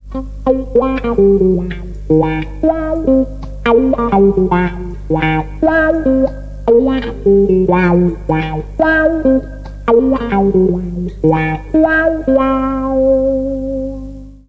wah-clean.mp3